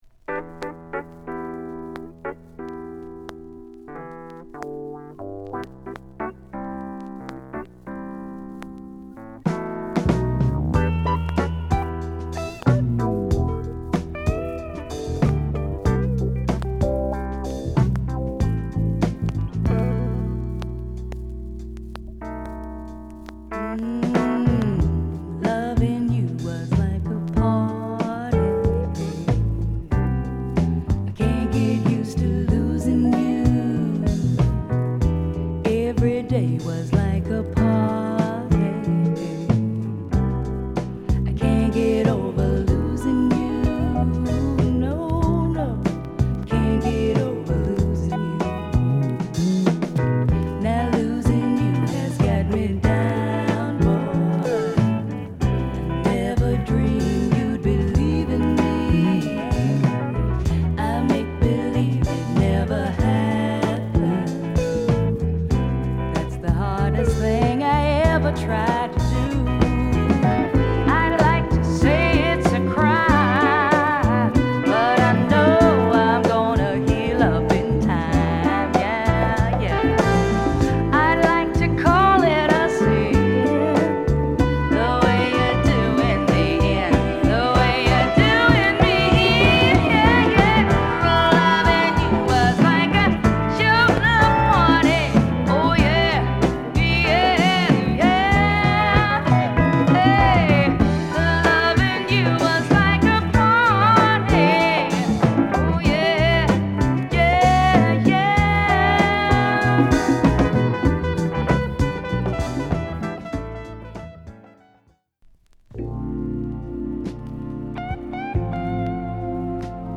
ジワジワと盛り上がるメロウヴォーカル曲
シットリしたギターが沁みるスロウ